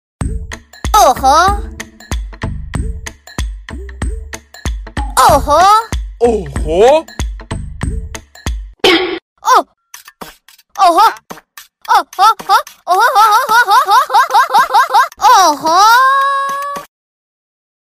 SFX哦嚯音效下载
SFX音效